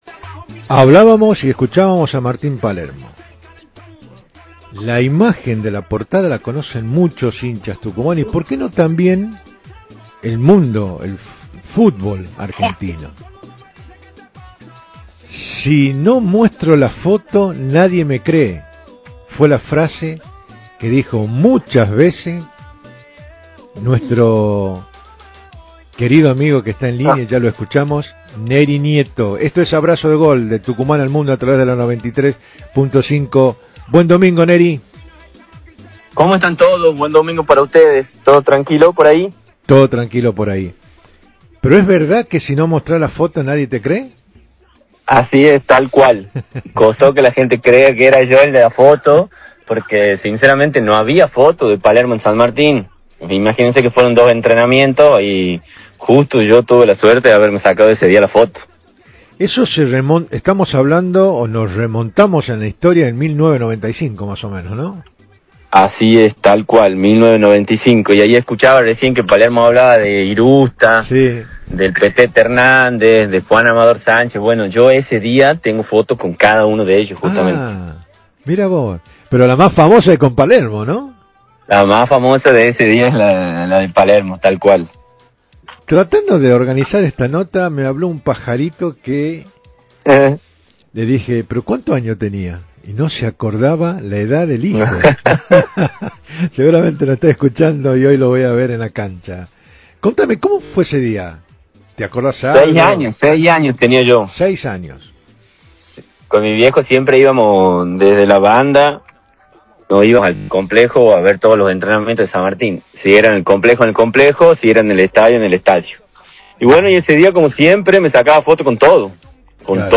Fragmento de la entrevista